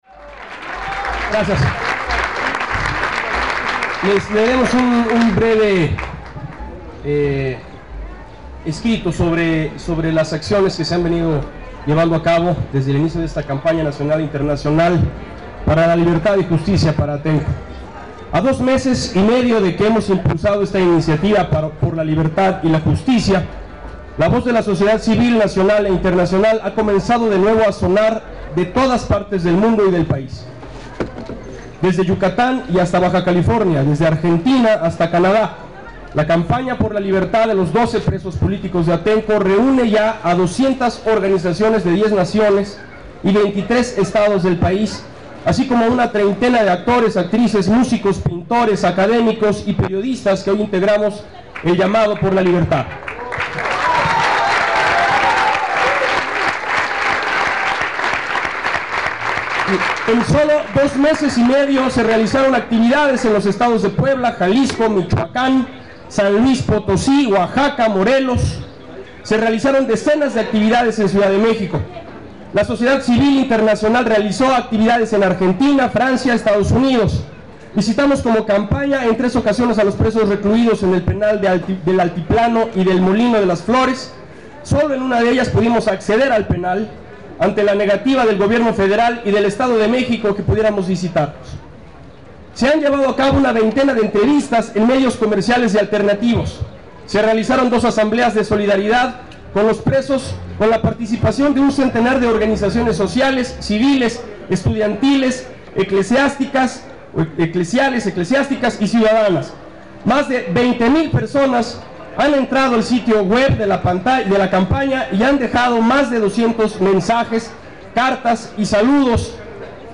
Cientos de personas asistieron a recordar 3 años de la brutal represión sufrida por el pueblo de Atenco el 3 y 4 de mayo de 2006.
- Palabras de Bruno Bichir y Daniel Giménez Cacho